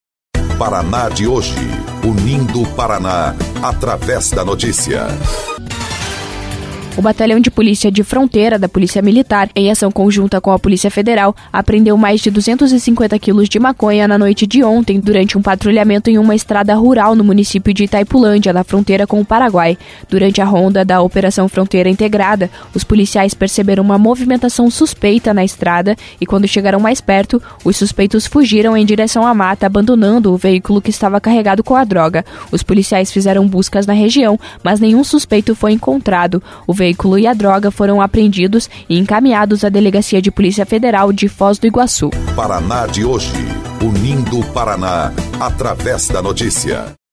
01.09 – BOLETIM – Operação Fronteira Integrada apreende mais de 250 quilos de maconha